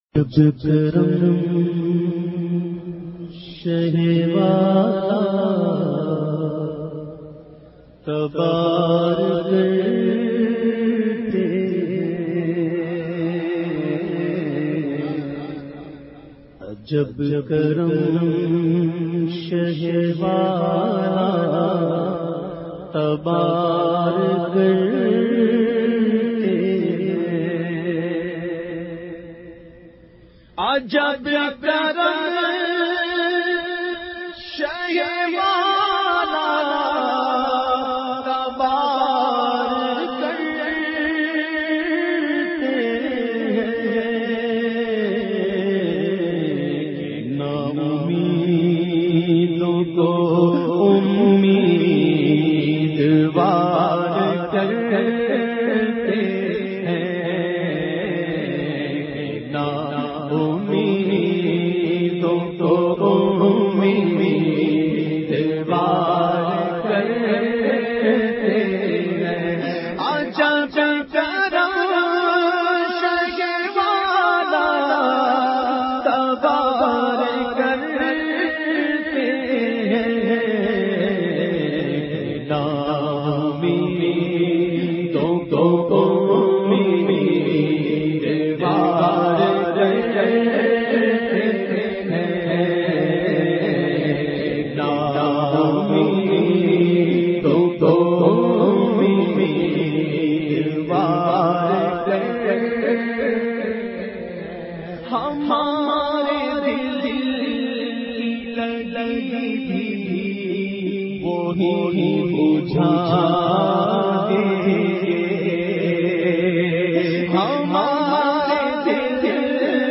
The Naat Sharif Ajab Karam Shah e Wala recited by famous Naat Khawan of Pakistan Owais Raza Qadri.